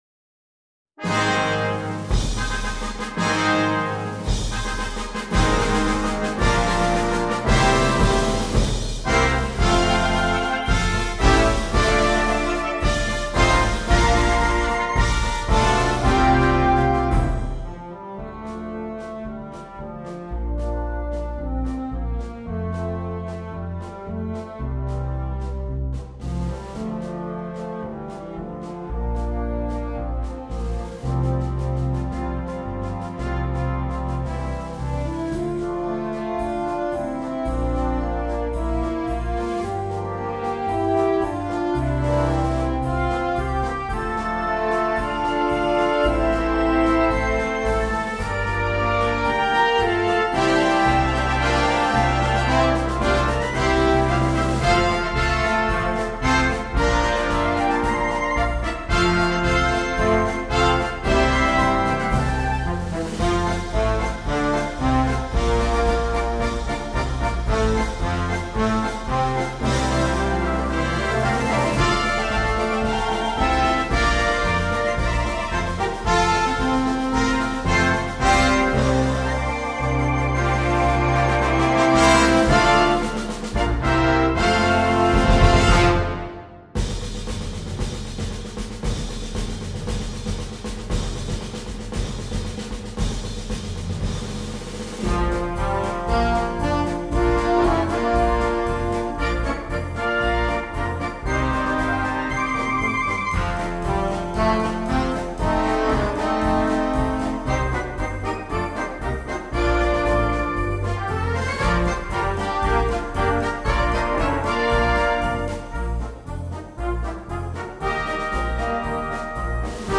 2. Orchestre d'Harmonie
sans instrument solo
marches